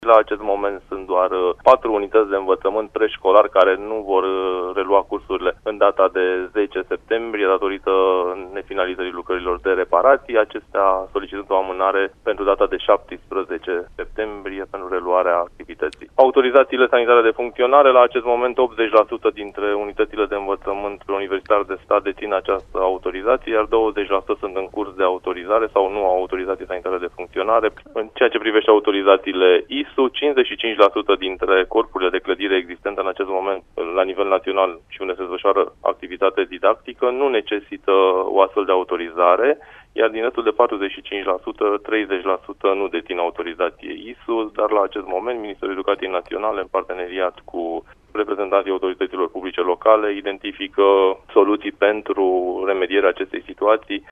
Secretarul de stat în Ministerul Educaţiei, Florian Lixandru, a explicat, pentru Radio România Actualităţi, care este situaţia şcolilor la nivel naţional: